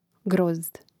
grȍzd grozd